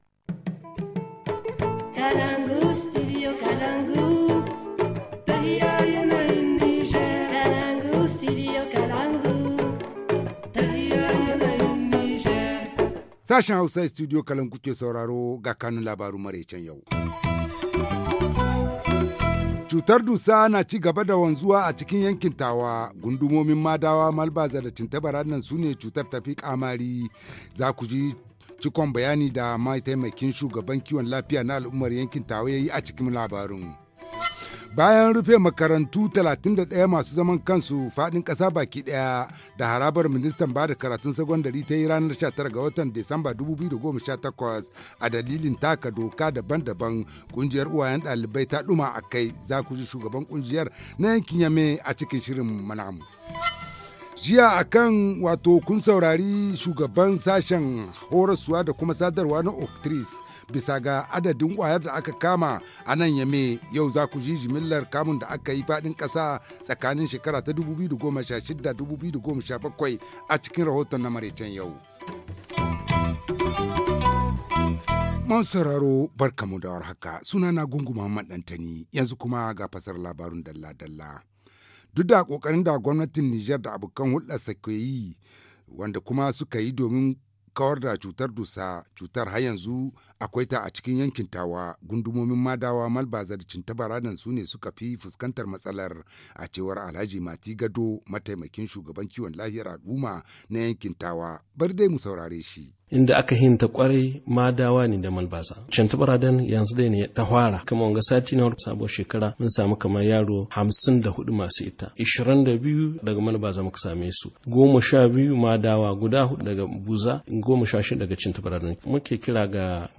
Le journal du 11 janvier 2019 - Studio Kalangou - Au rythme du Niger